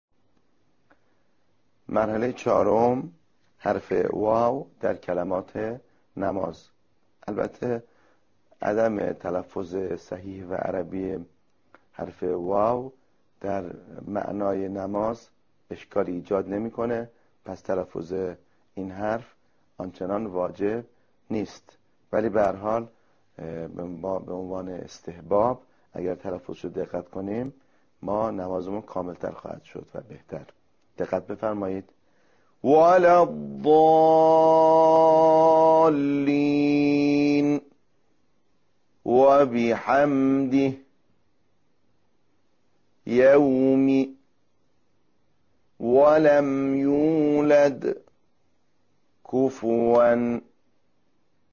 این حرف از بین دو لب، بدون دخالت دندان ها به تلفظ در می‌آید.
تمرین عملی_مرحله ۴
💠تلفظ حرف «و»💠